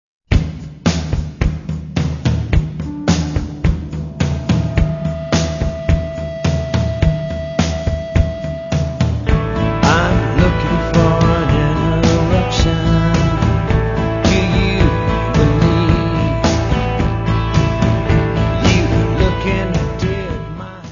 : stereo; 12 cm + folheto
Área:  Pop / Rock